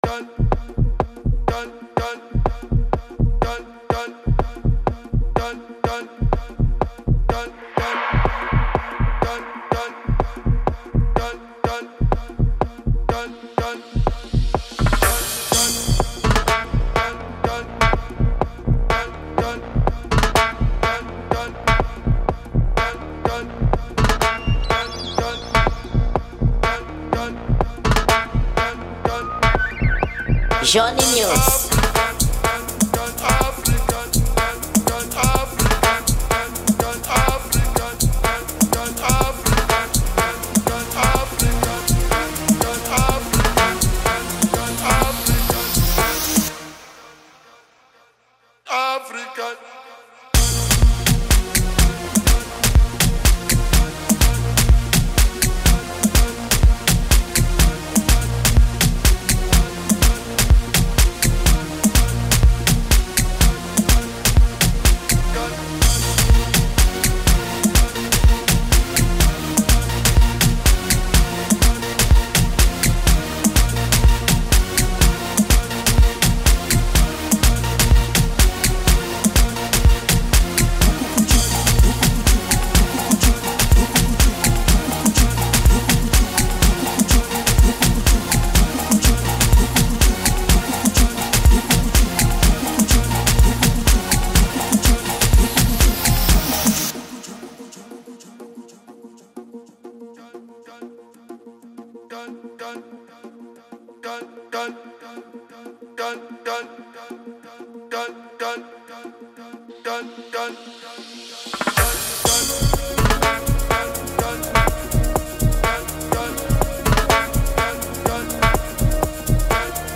Genero: Afrobeat